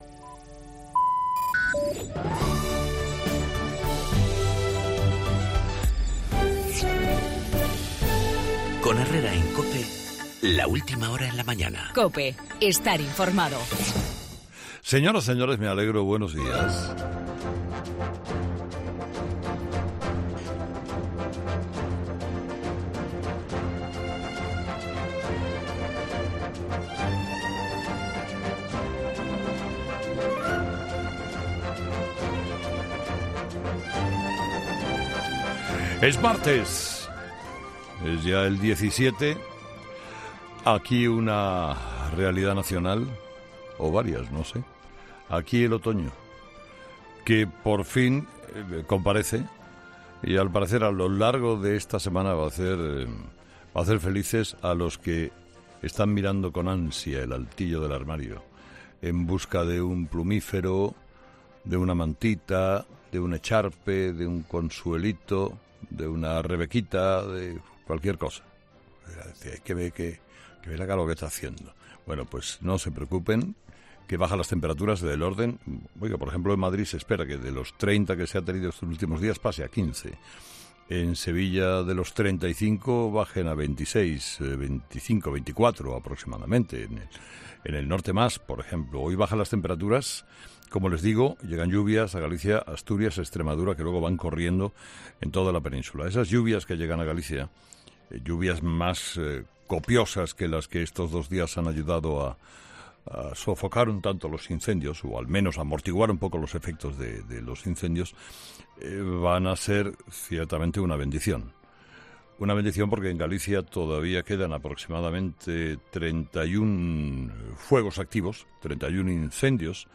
Monólogo de las 8 de Herrera
La puesta en libertad con medidas cautelares del mayor de los Mossos en contra del criterio de la Fiscalía, en el editorial de Carlos Herrera